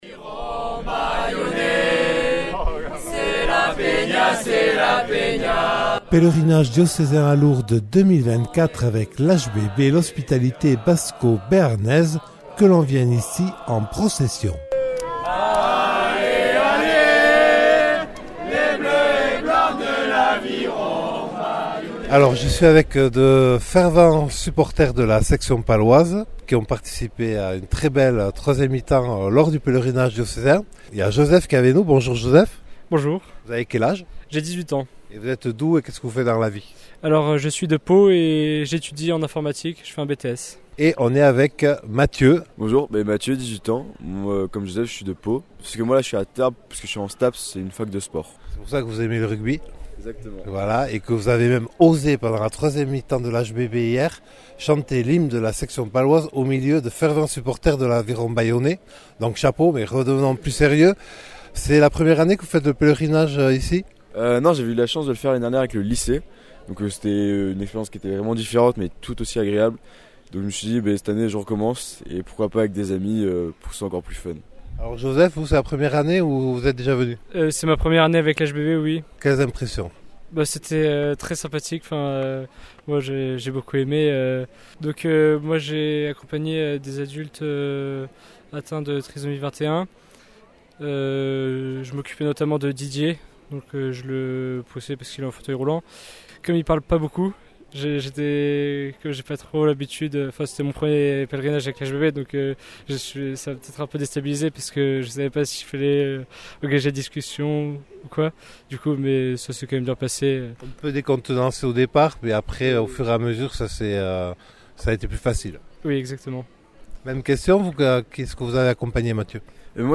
4ème reportage : deux jeunes étudiants de Pau ; HBB Oloron ; HBB Asson, Igon et Pau.